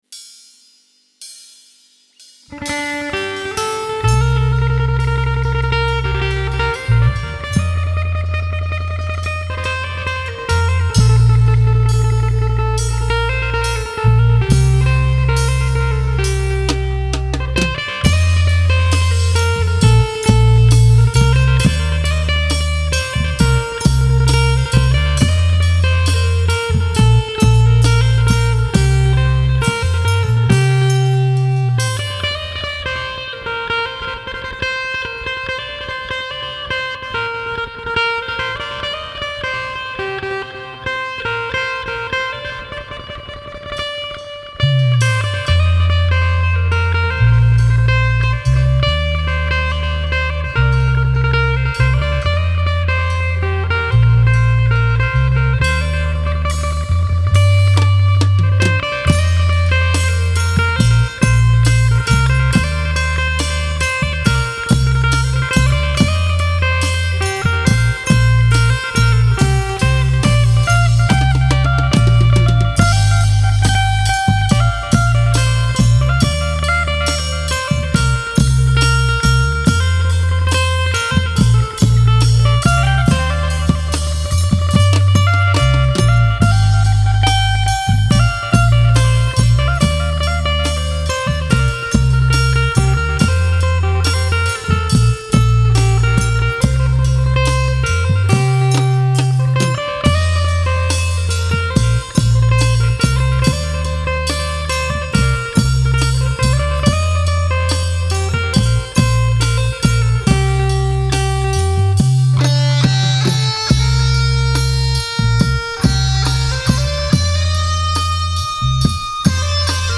Genre: World, Tai